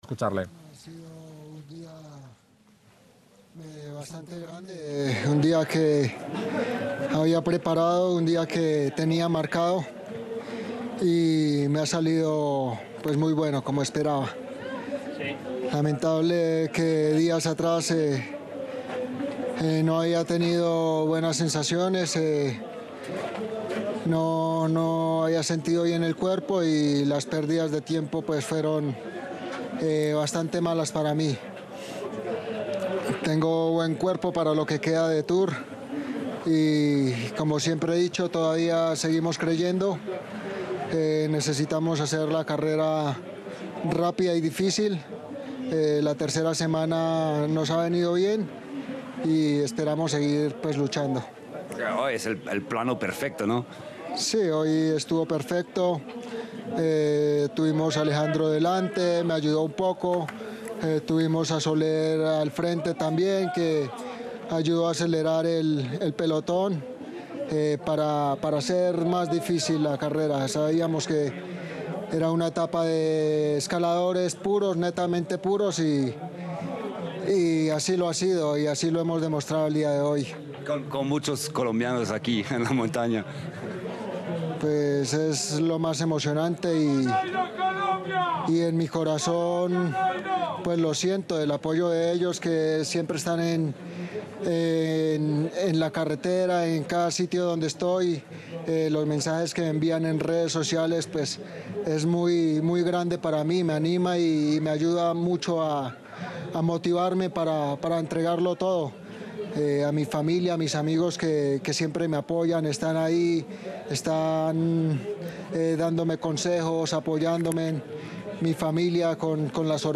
“Ha sido un día bastante grande, un día que había preparado, que tenía marcado y me ha salido muy bueno, como esperaba. Lamentable que días atrás no había tenido buenas sensaciones, no había sentido bien el cuerpo”, señaló el ciclista boyacense que se adjudica su tercera etapa de Tour de Francia en las versiones que ha participado.
Audio-Nairo-Quintana-ganador-etapa-17-Tour-de-Francia.mp3